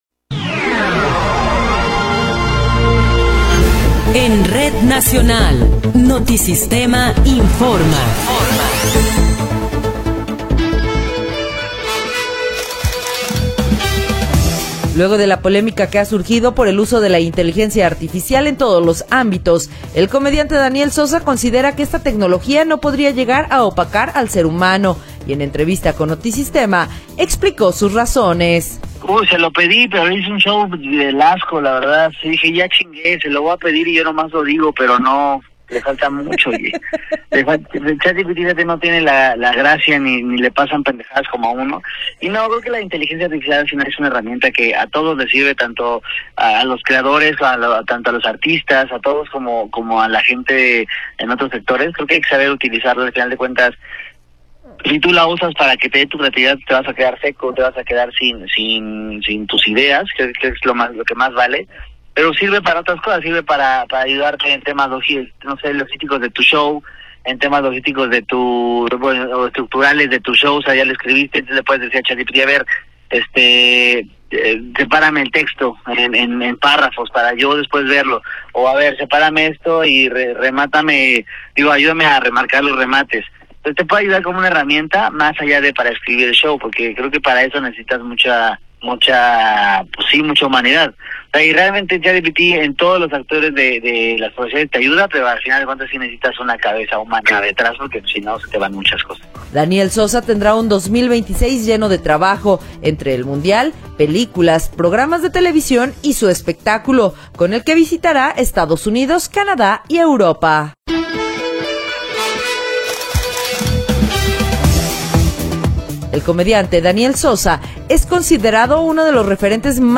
Noticiero 10 hrs. – 1 de Enero de 2026